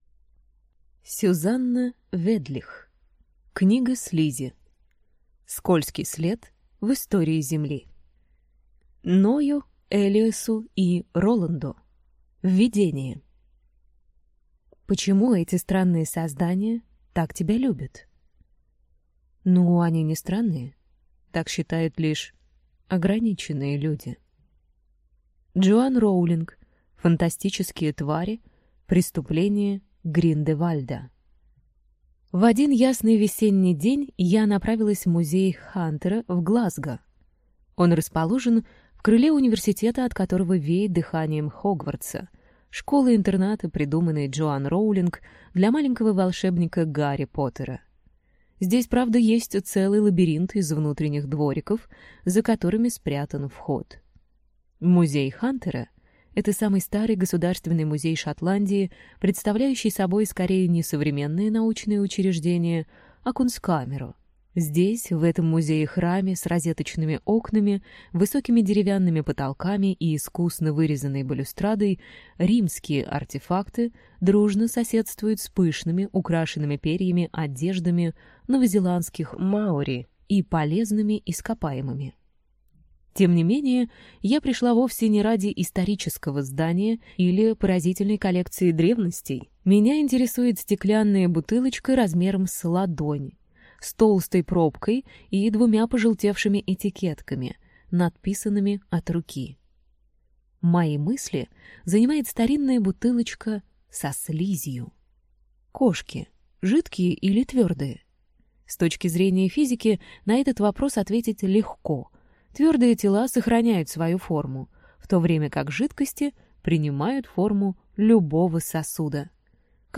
Аудиокнига Книга слизи. Скользкий след в истории Земли | Библиотека аудиокниг